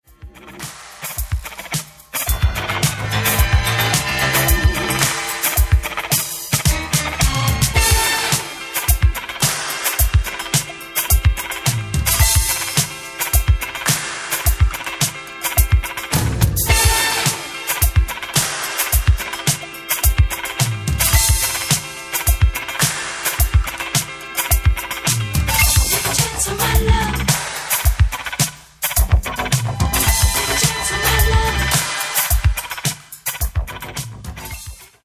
12''Mix Extended
Genere:   Disco | Funky